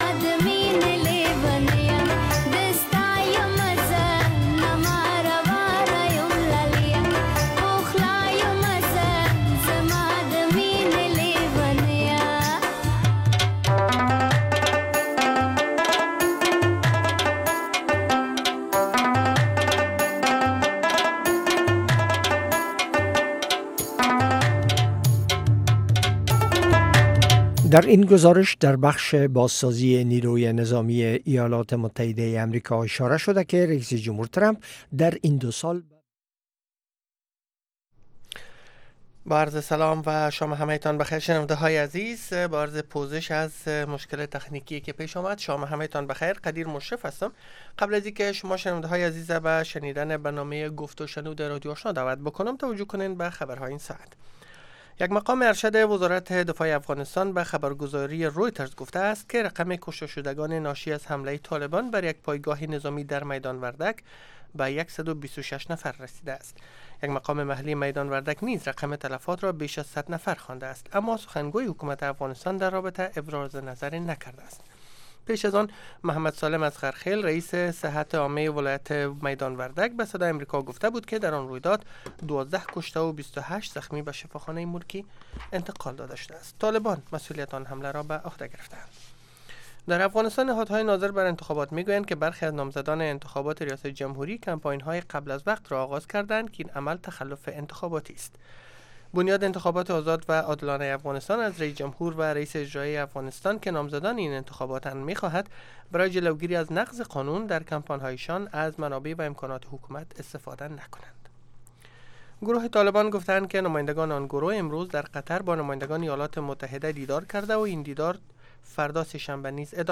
گفت و شنود بحث رادیویی است که در آن موضوعات مهم خبری با حضور تحلیلگران و مقام های حکومت افغانستان به بحث گرفته می شود. گفت و شنود به روزهای سه شنبه و جمعه به ترتیب به مسایل زنان و صحت اختصاص یافته است.